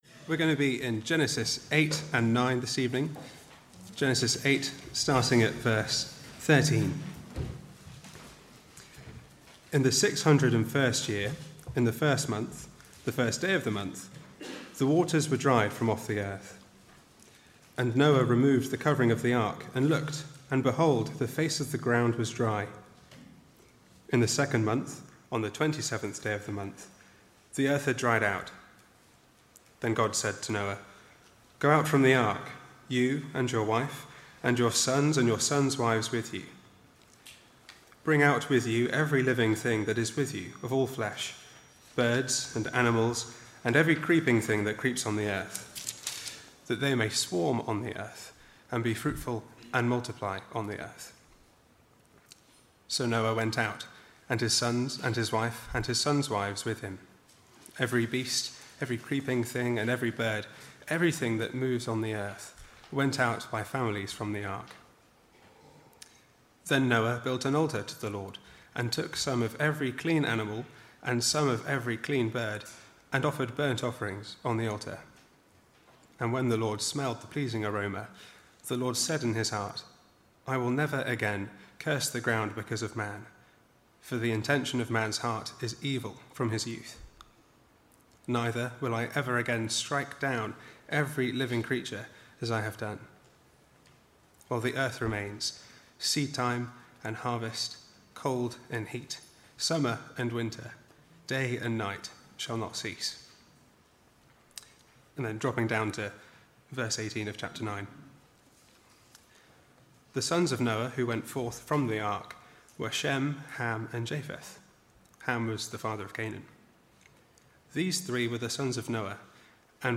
2019 Autumn Lectures